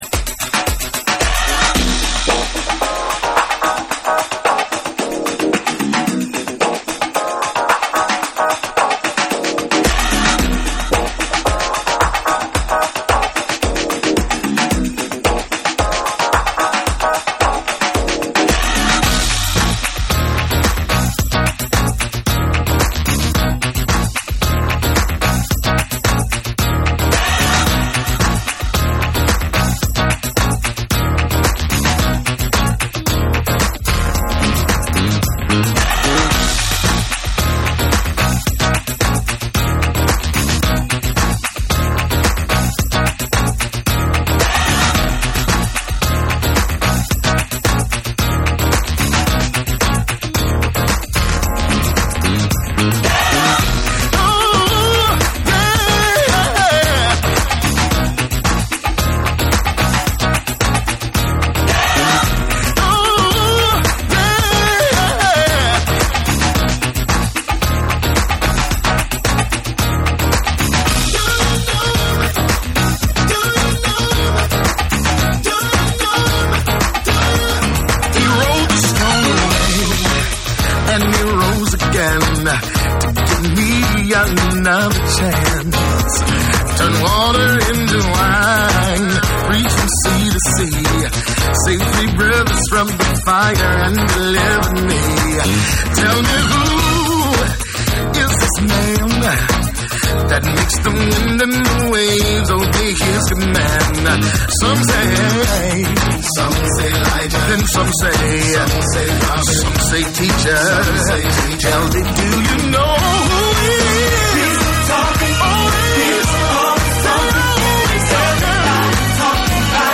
ガラージ・ライクなダビーでエモーショナルなディスコ・ブギー・ナンバー
DANCE CLASSICS / DISCO / RE-EDIT / MASH UP